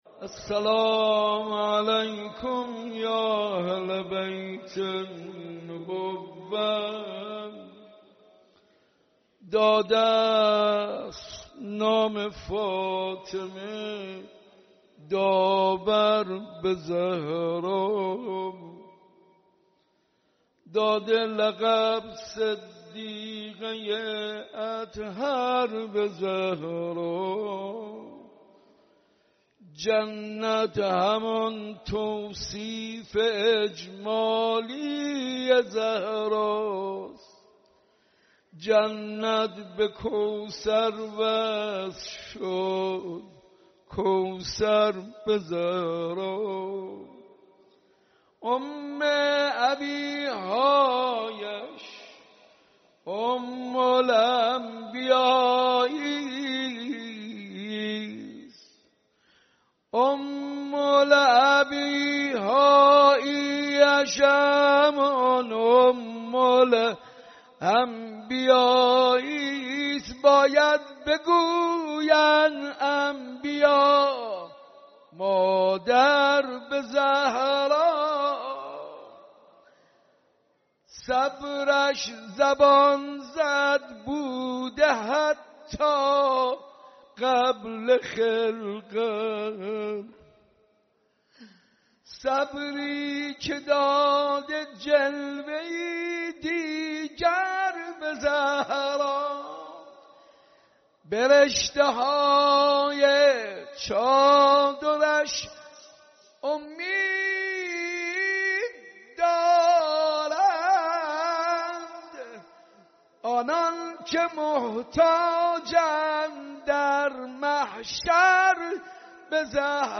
صوت/ مداحی حاج منصور ارضی در اولین شب عزاداری حسینیه امام خمینین(ره)